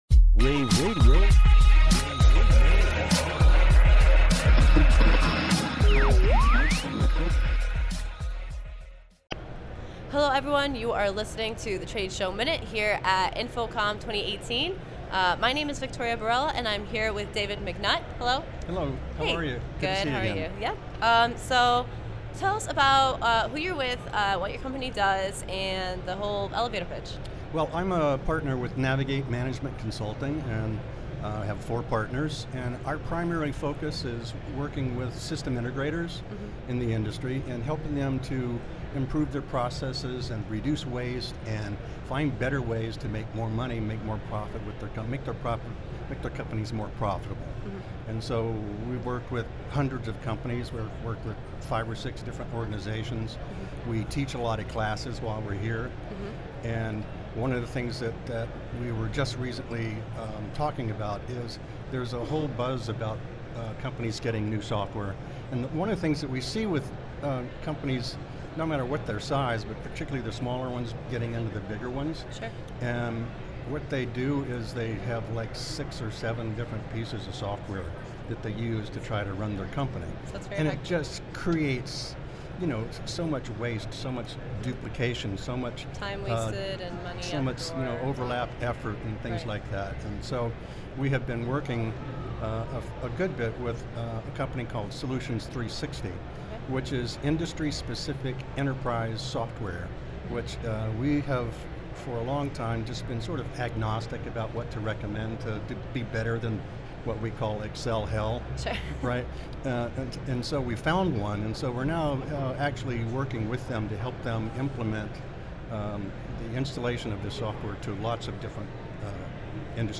InfoComm Day2_showmin-243.mp3